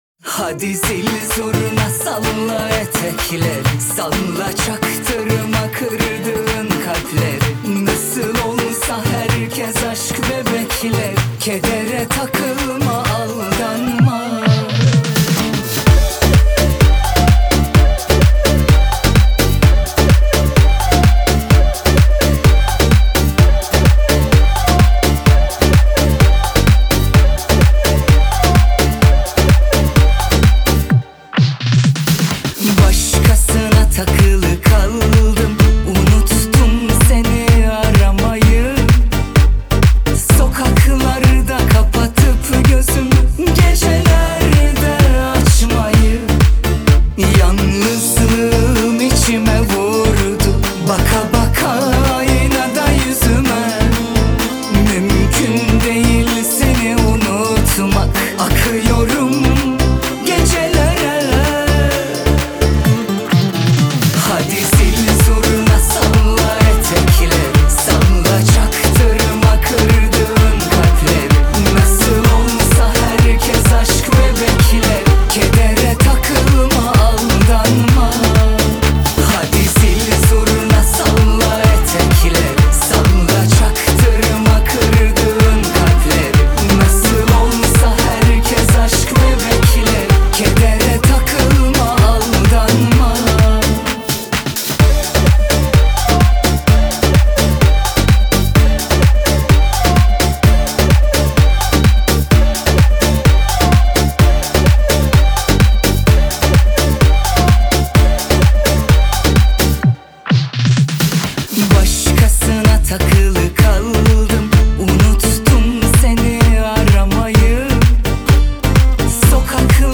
Tür: Türkçe / Pop